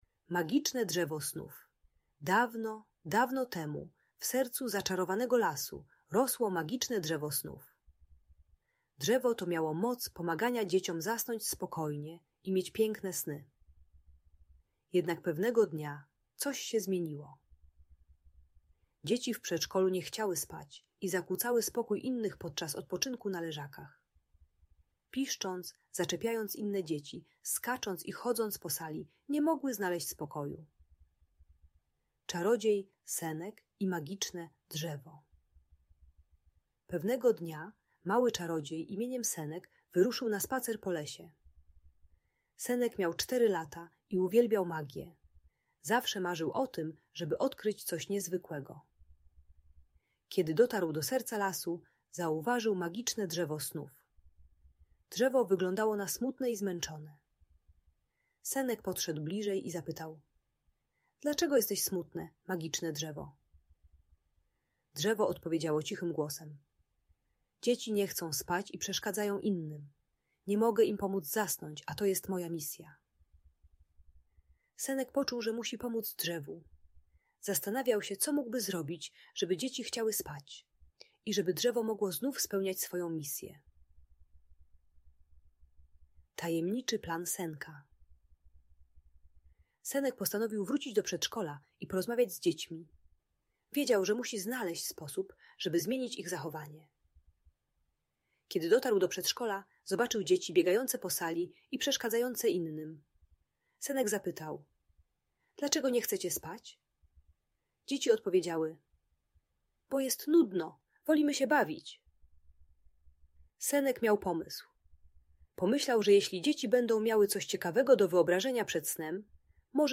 Magiczne Drzewo Snów - Zaczarowana opowieść dla dzieci - Audiobajka